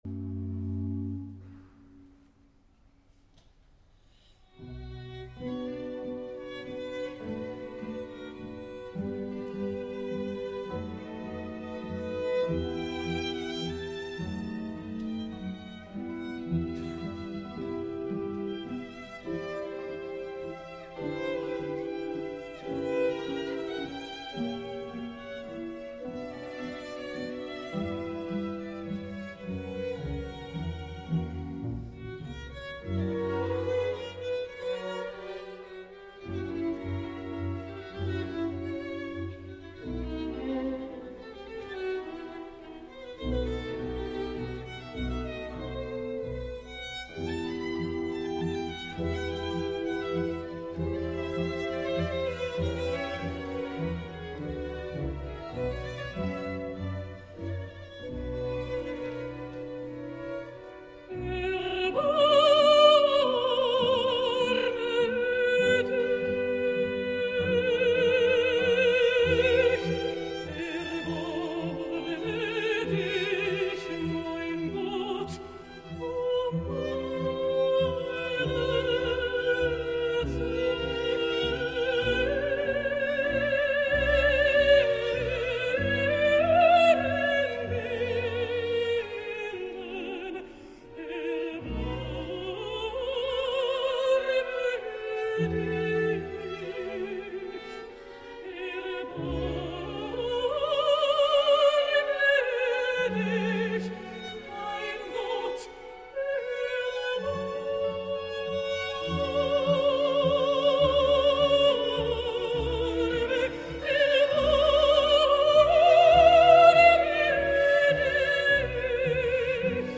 Musicalment la versió és magnífica, com ja ho era a Birminghan i com també ho era la de Sant Joan a Baden-Baden, neta, transparent, pura filigrana sonora gràcies a una orquestra de somni, amb els obligats orquestrals d’una consistència i fermesa admirable. Lluny de les sonoritats historicistes i sense ser de caire romàntic, és una tercera via.
Magdalena Kožená mezzo-soprano
Berliner Philharmoniker
Director musical Sir Simon Rattle
Royal Albert Hall, Londres 6 de setembre de 2014